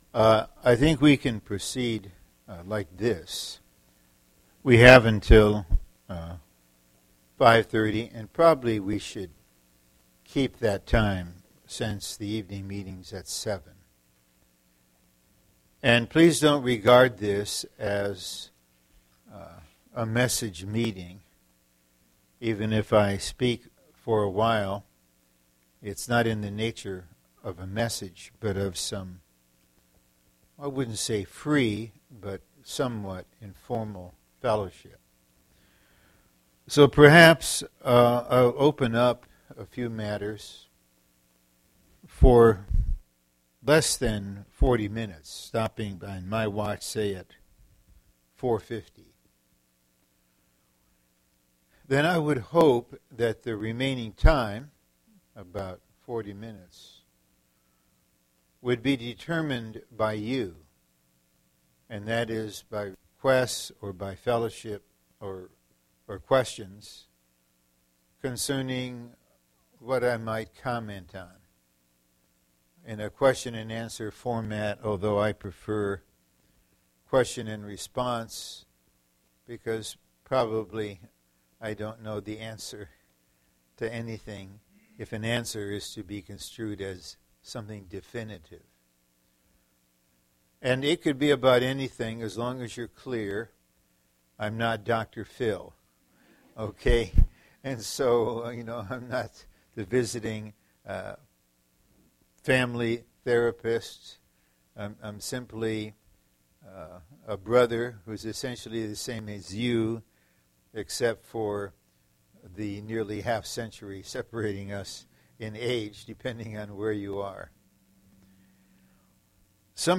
The link below is to a working saints fellowship time in Madison, WI in March 2011.